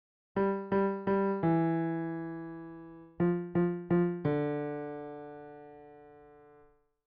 Beethovens-5th-Symphony-piano-motif.mp3